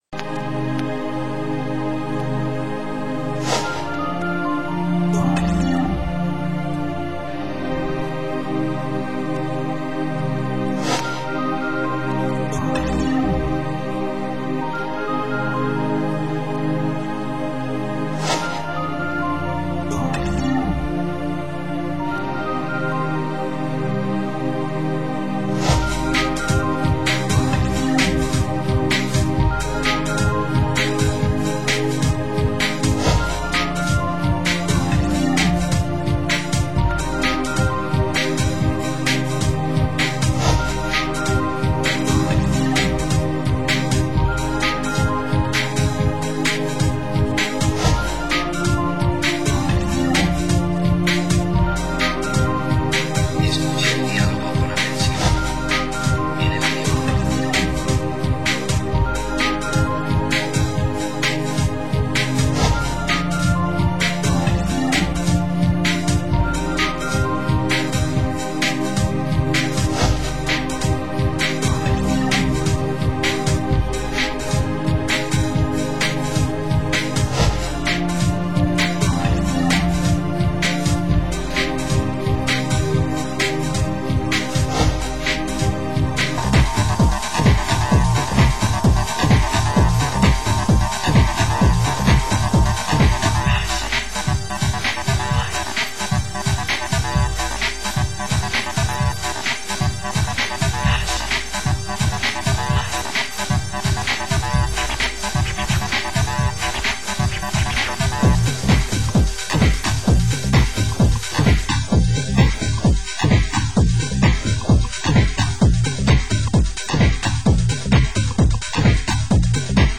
Format: Vinyl 12 Inch
Genre: US Techno